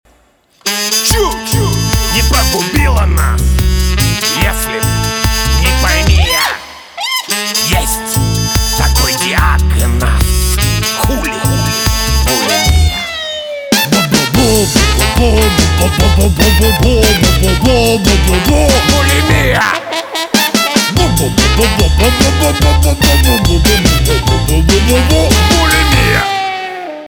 русский рок , гитара , труба , барабаны